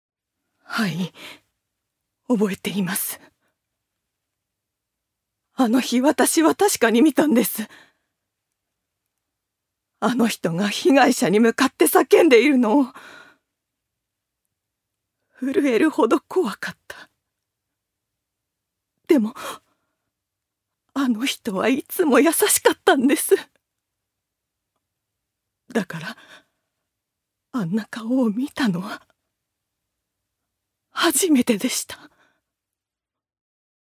ジュニア：女性
セリフ３